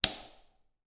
Звуки переключателя, выключателя
Выключатель света в гараже с эффектом реверберации